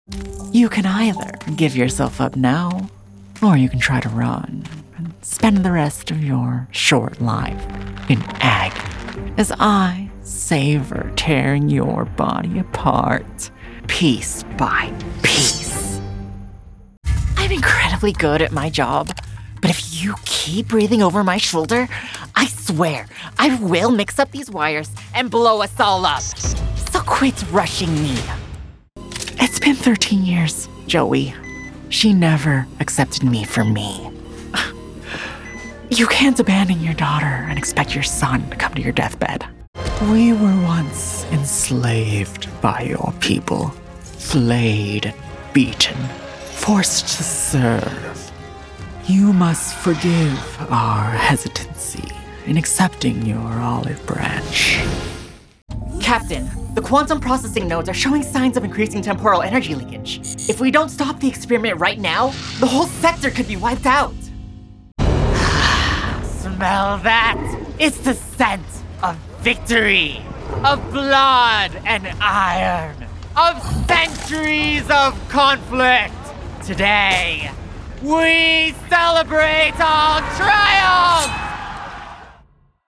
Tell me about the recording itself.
• Rode NT1-A • 3’ x 5’ Booth with Sound Dampening Blankets